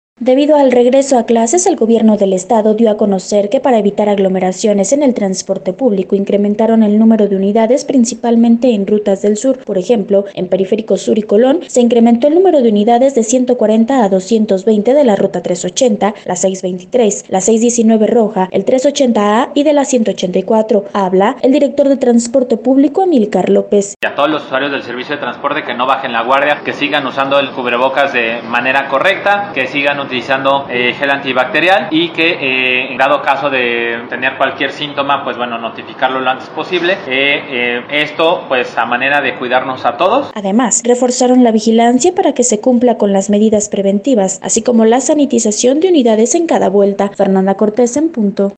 Habla el director de transporte público Amílcar López: